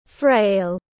Yếu ớt dễ tổn thương tiếng anh gọi là frail, phiên âm tiếng anh đọc là /freɪl/ .
Frail /freɪl/